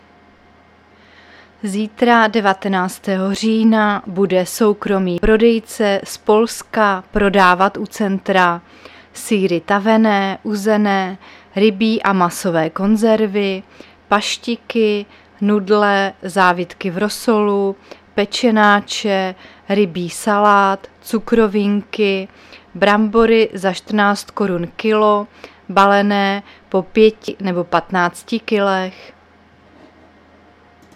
Záznam hlášení místního rozhlasu 18.10.2023
Zařazení: Rozhlas